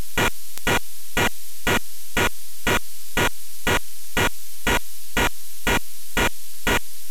Cette version a été mise au point pour les sorties non filtrées (sortie discri.) des récepteurs.